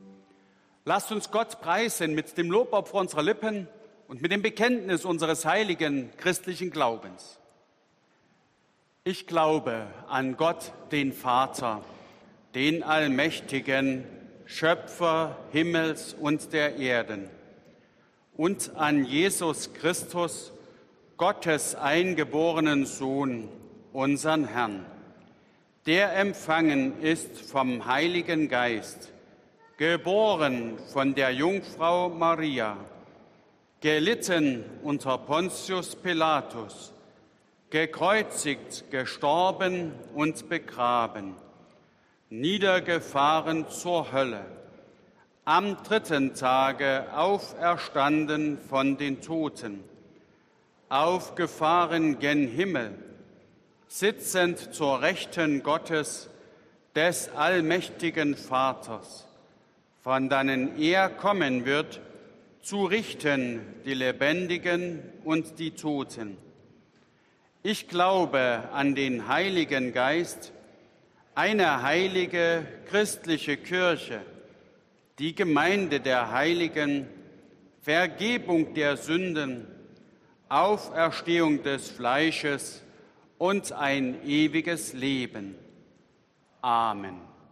Audiomitschnitt unseres Gottesdienstes vom Sonntag Invokavit 2022.